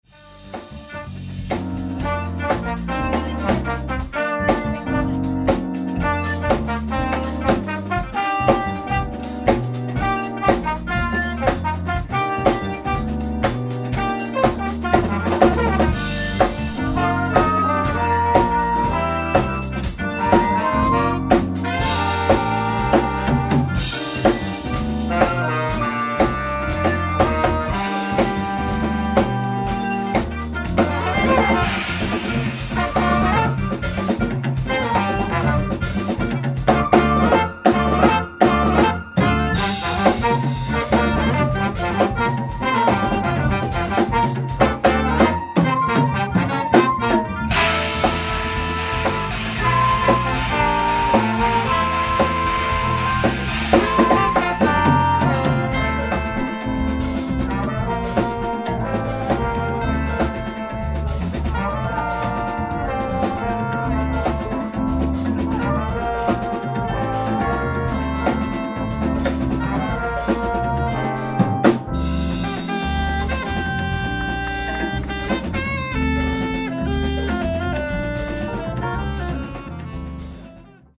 ライブ・アット・ブルーノート・クラブ、ニューヨーク 03/23/2025
往年のメンバーでの最新ライブ！！
※試聴用に実際より音質を落としています。